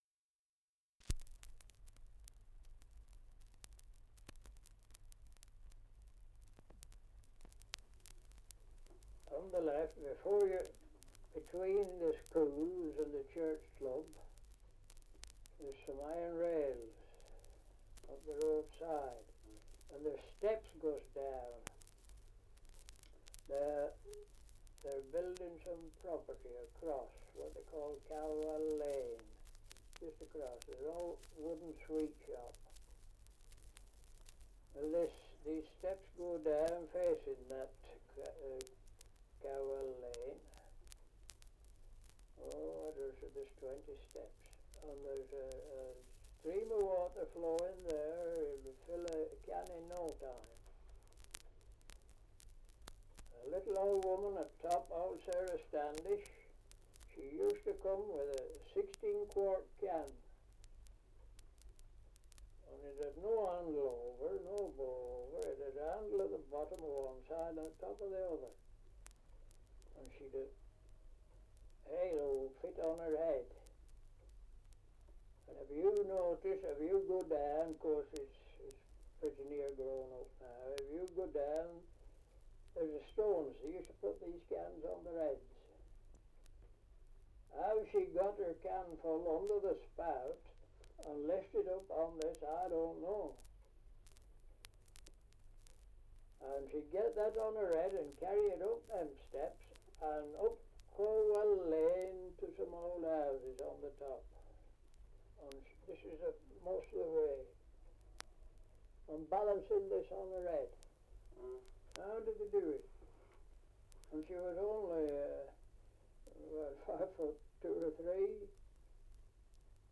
Dialect recording in Whittle-le-Woods, Lancashire
78 r.p.m., cellulose nitrate on aluminium